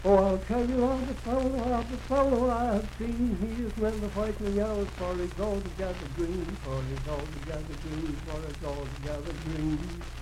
Unaccompanied vocal music
Verse-refrain 1(6).
Voice (sung)